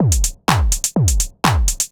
Network Beat_125.wav